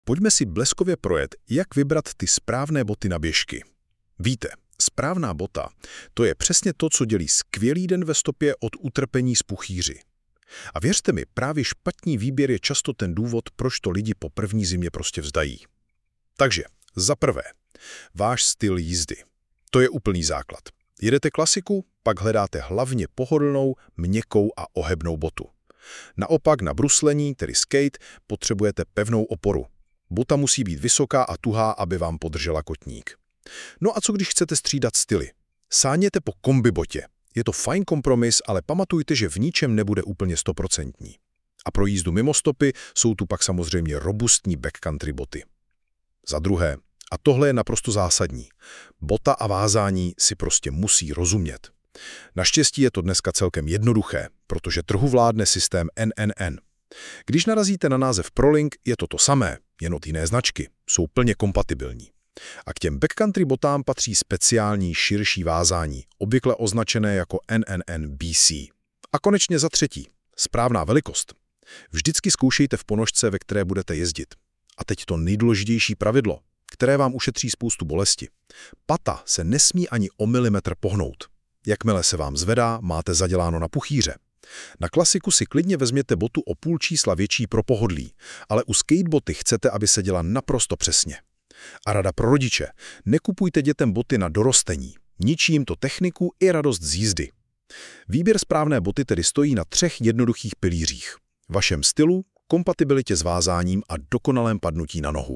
🎧 Alex AI radí